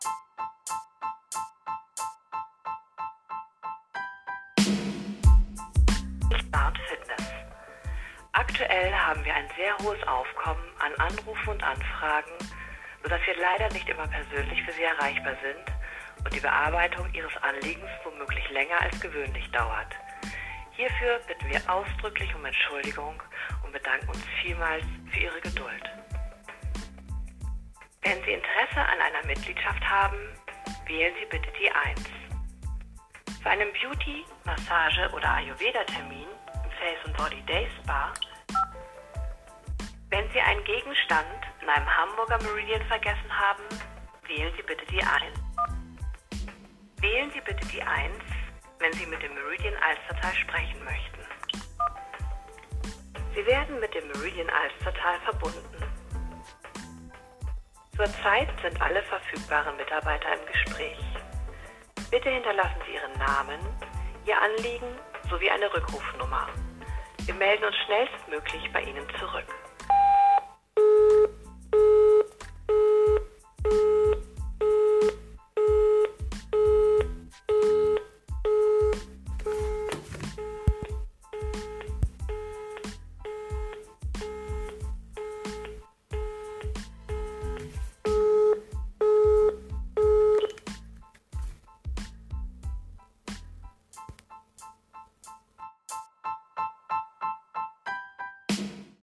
Und doch ist die Ansage vom Band immer gleich: Der Service überlastet, kein Mitarbeiter verfügbar, die Voicemail voll und auf jeden Fall nicht wie angekündigt bereit, Anliegen und Kontaktdaten aufzunehmen.